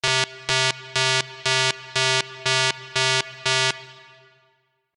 جلوه های صوتی
دانلود صدای هشدار 10 از ساعد نیوز با لینک مستقیم و کیفیت بالا